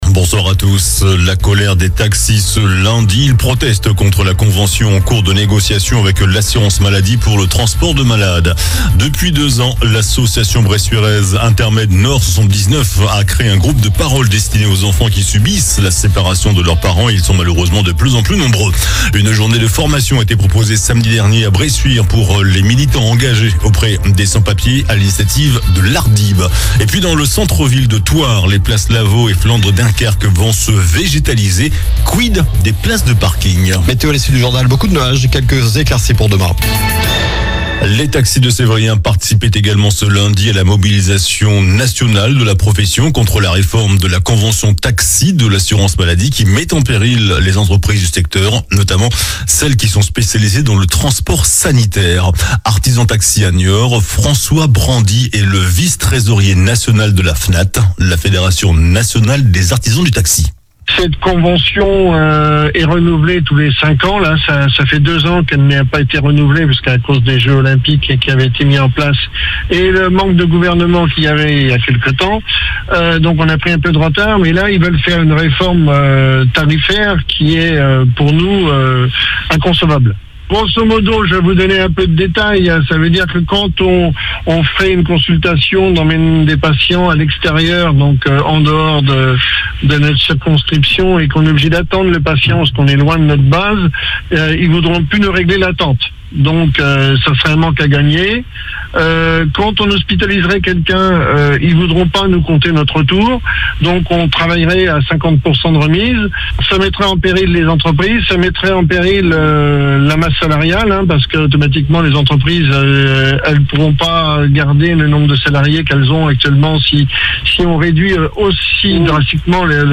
JOURNAL DU LUNDI 02 DECEMBRE ( SOIR )